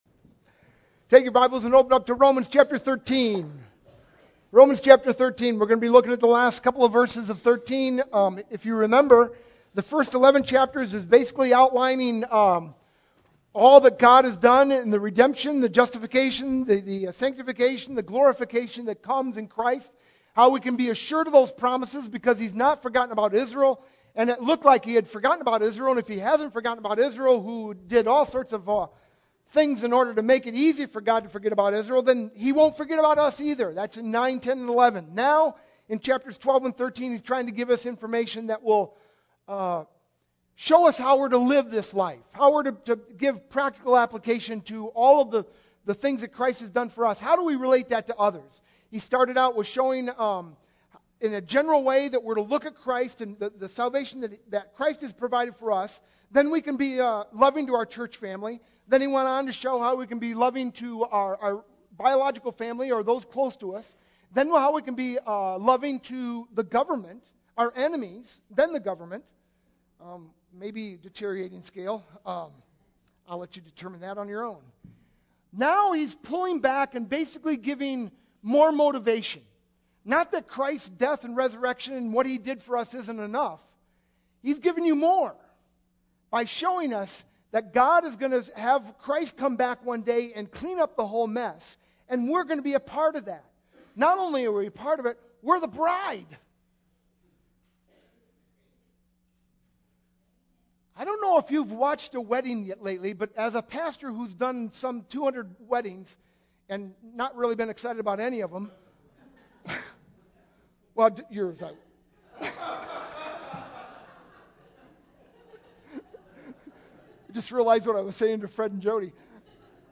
sermon-1-29-12.mp3